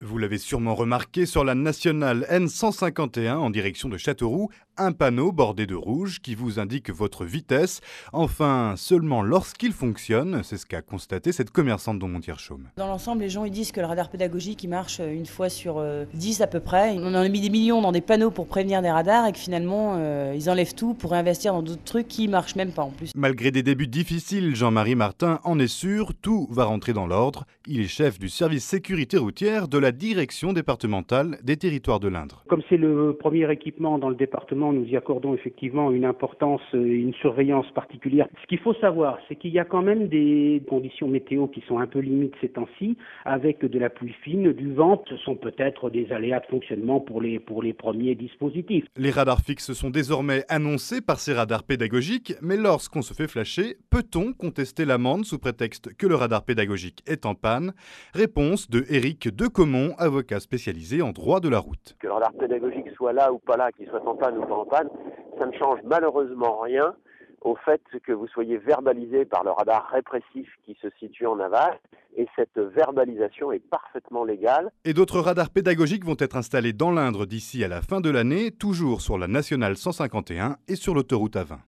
Dans un reportage de France Bleu Berry à propos d’un radar pédagogique sur la N.151 présentant des dysfonctionnements importants